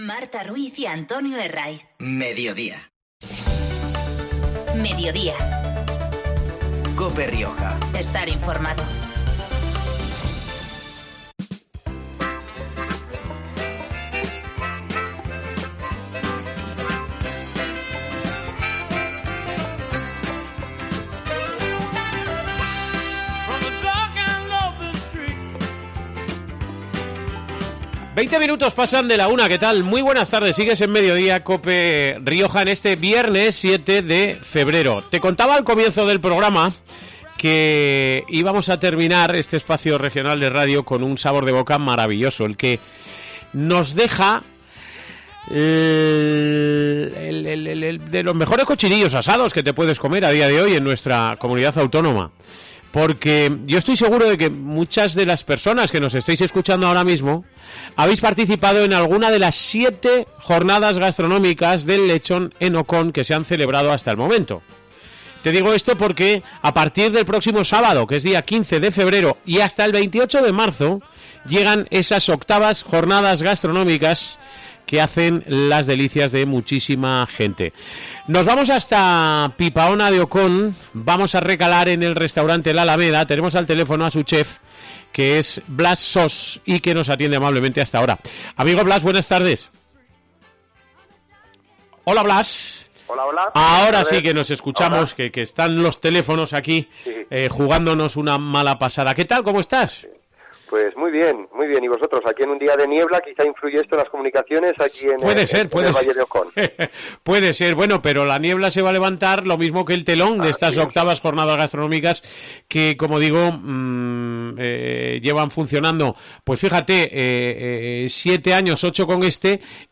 con quien hemos podido hablar este mediodía en COPE Rioja. El cocinero realiza también una explicación de la zona, del municipio y valle de Ocón, y de la Reserva de la Biosfera riojana en la que están enmarcados estos pueblos, que es de donde provienen la mayor parte de los productos que ofrecen en el restaurante.